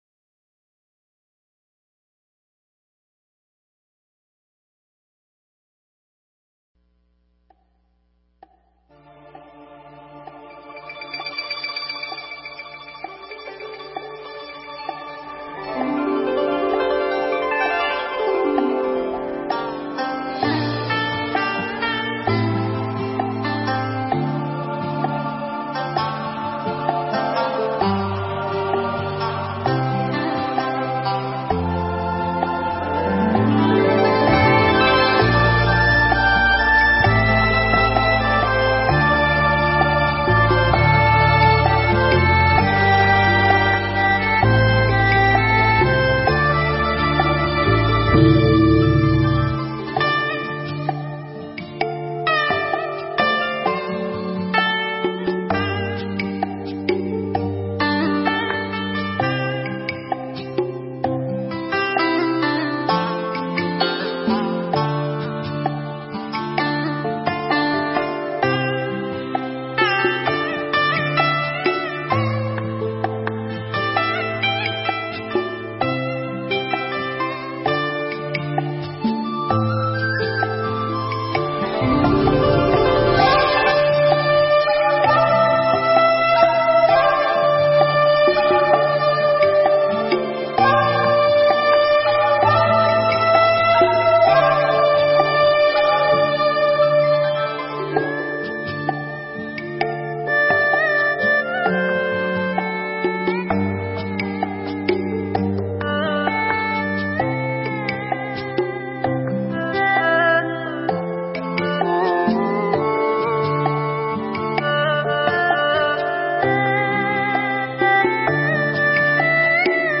Nghe Mp3 thuyết pháp Bảy Yếu Tố Giác Ngộ